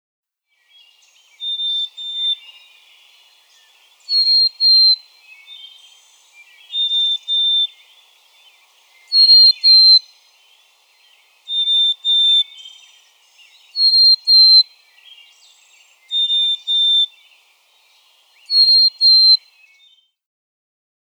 Black-capped chickadee
♫256. One of several Martha's Vineyard dialects off the coast of Massachusetts; this male alternates two different songs during the dawn chorus.
Aquinnah (formerly Gay Head), Martha's Vineyard, Massachusetts.
256_Black-capped_Chickadee.mp3